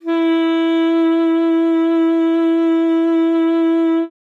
42e-sax06-e4.wav